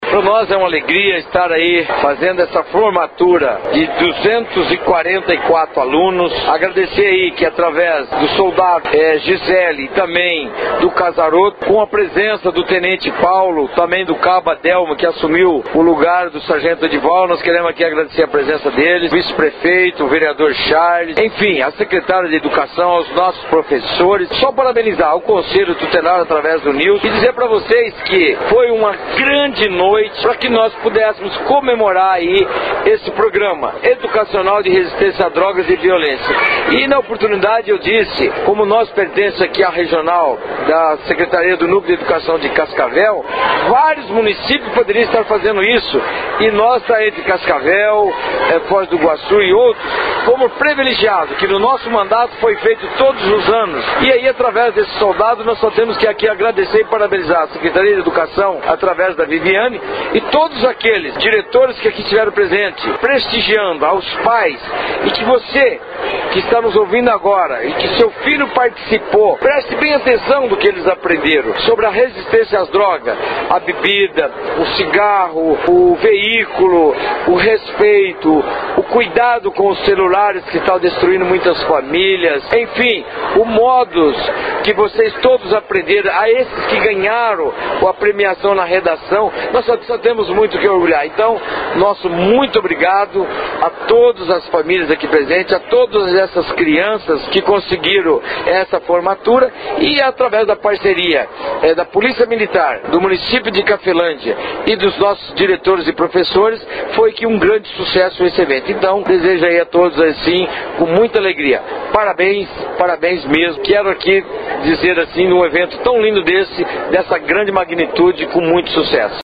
Conselheiro Tutelar Nilson Lima